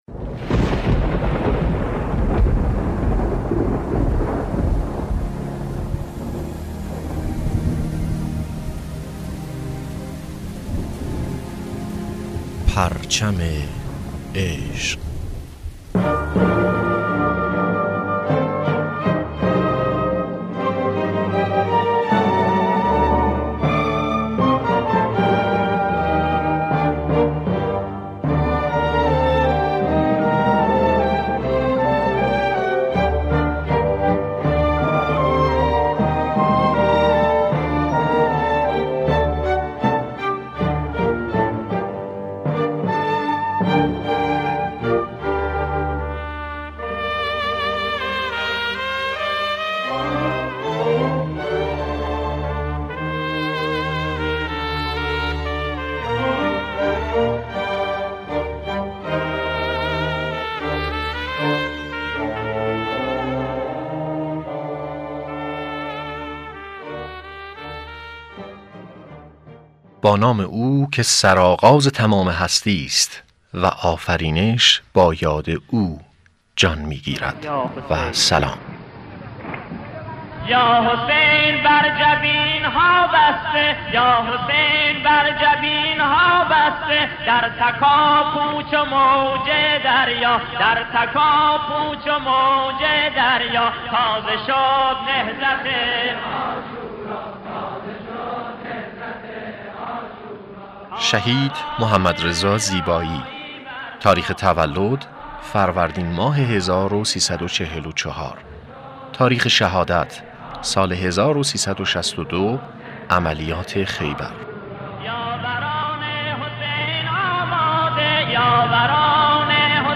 صوت مصاحبه